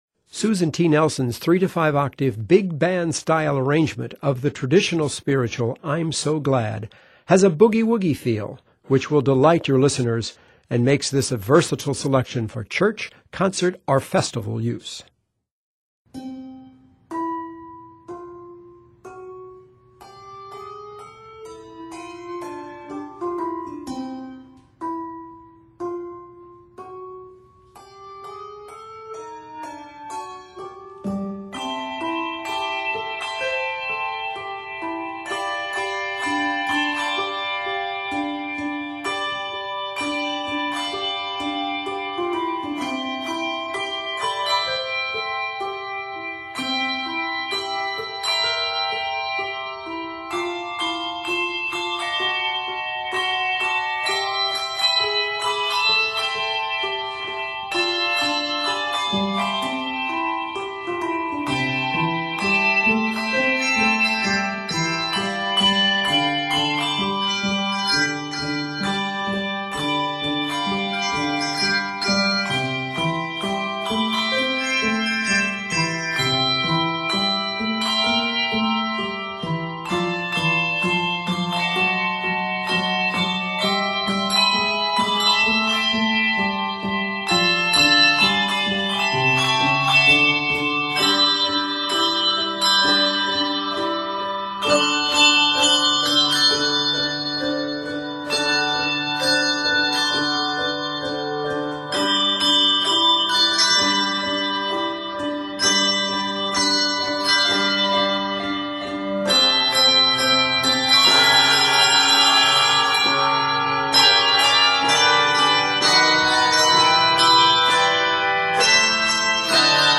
big band style arrangement
Scored in C Major, this piece is 81 measures.